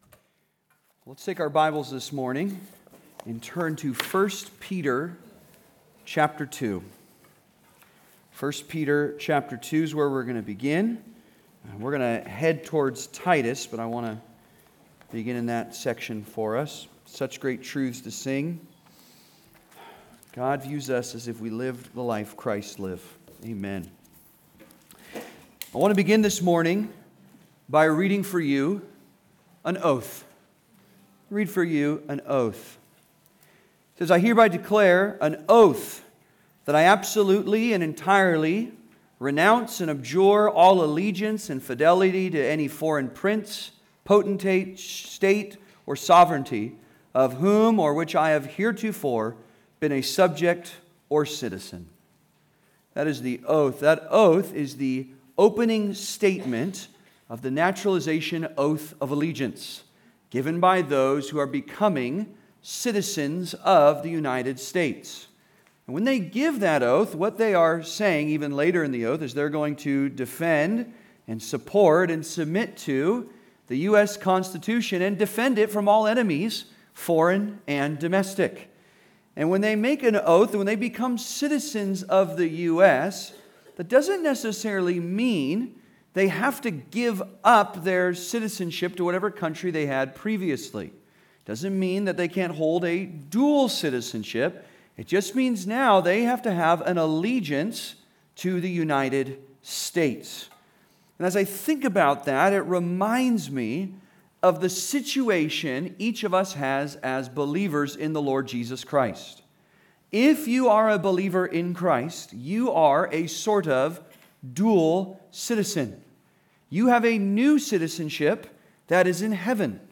Model Citizens (Sermon) - Compass Bible Church Long Beach